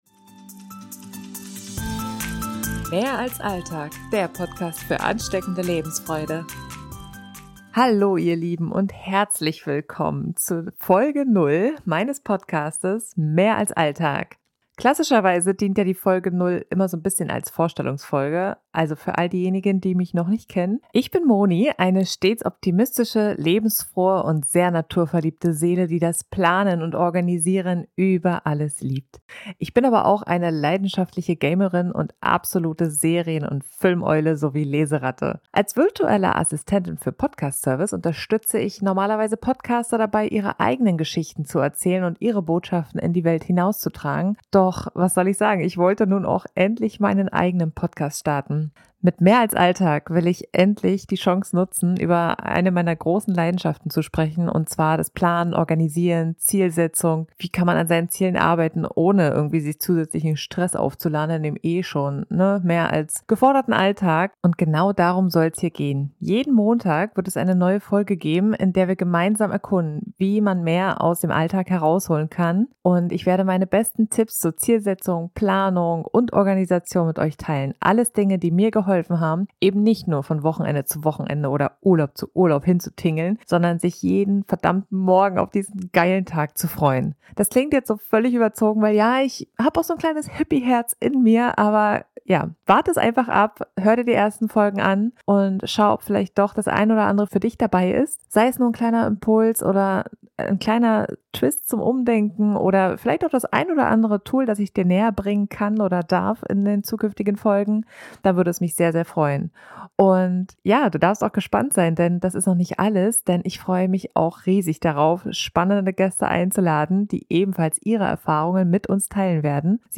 Intro-/Outromusik des Podcasts: